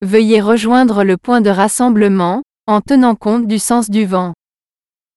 Exemples de messages vocaux